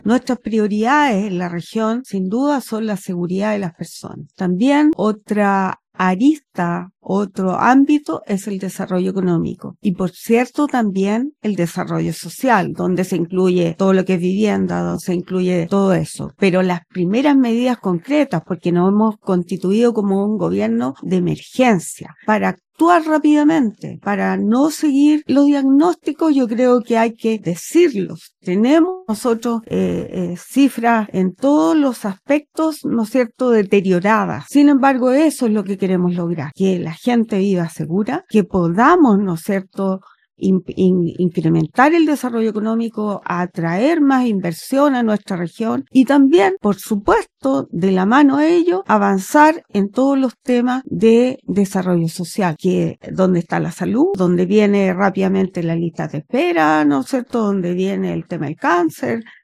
En entrevista exclusiva con Radio Paulina, la delegada presidencial regional de Tarapacá, Adriana Tapia Cifuentes, abordó la instalación del gobierno del Presidente José Antonio Kast en la región y los principales desafíos que enfrentará la nueva administración.